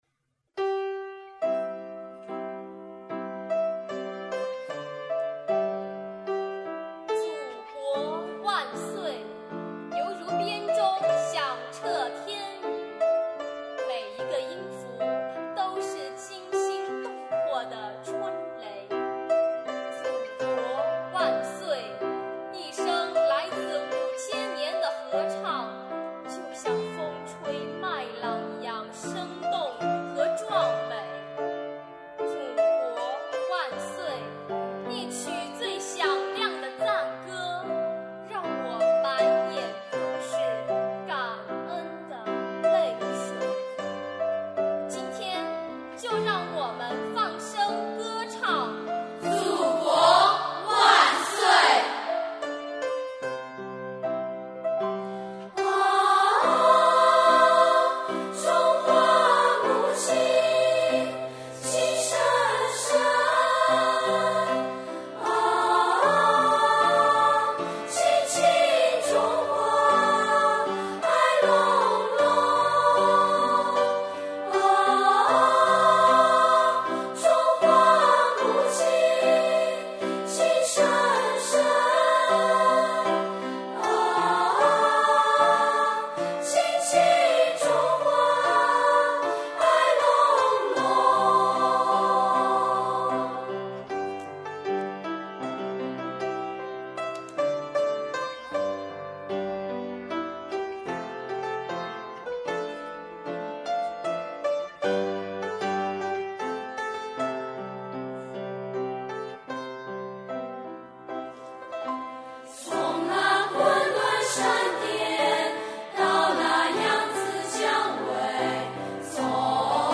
[18/10/2016]童声合唱《祖国万岁》作词瞿琮 作曲臧云飞